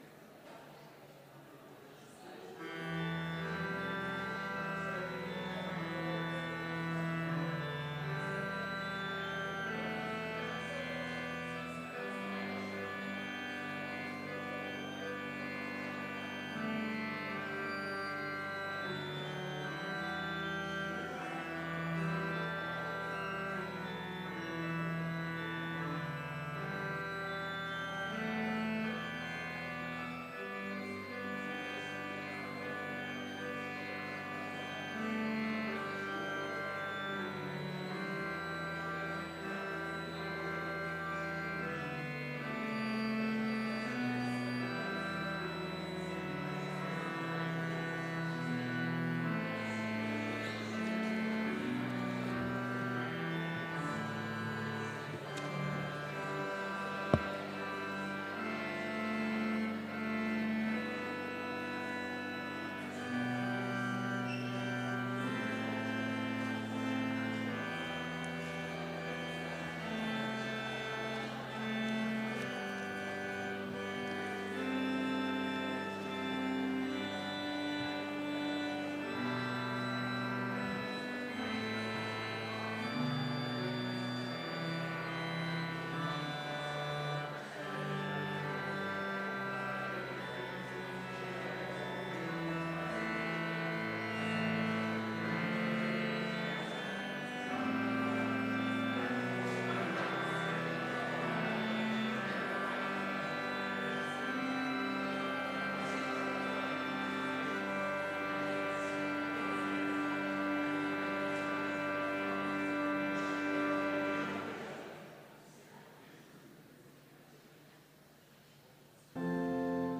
Complete service audio for Chapel - September 12, 2019